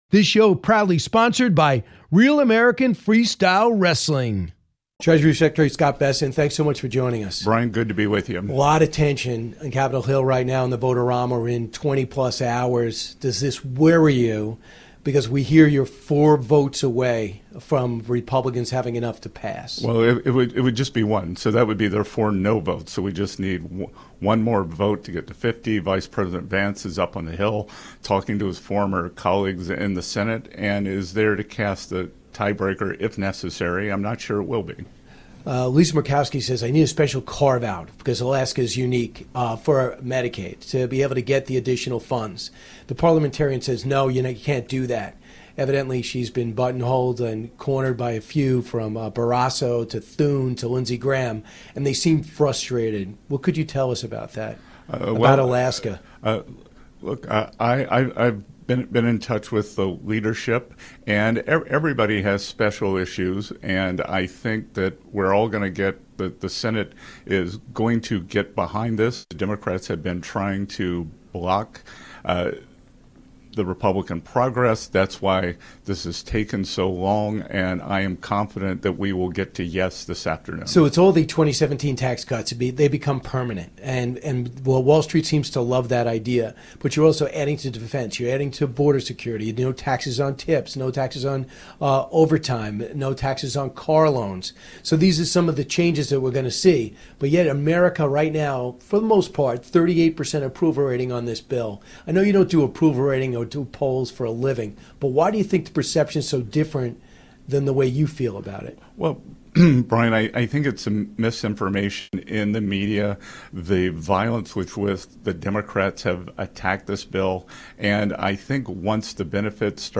Brian chats with Treasury Secretary Scott Bessent in the FOX & Friends green room!